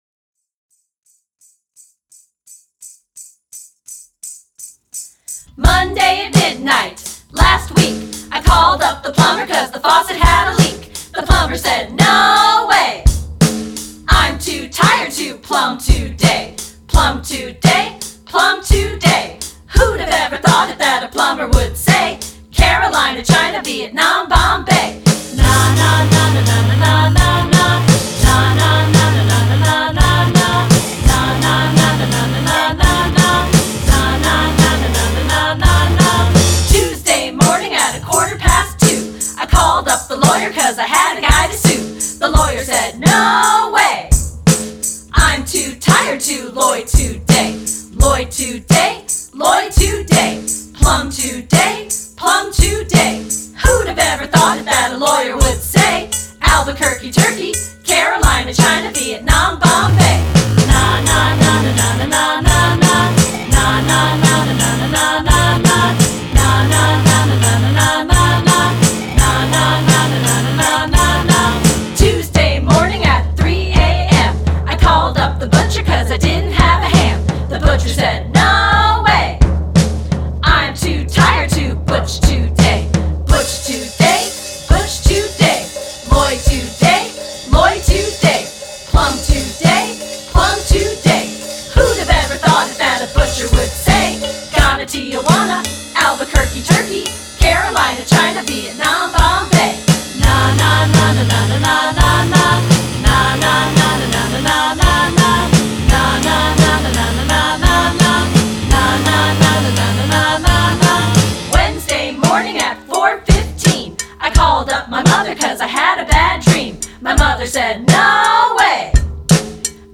vocals
drums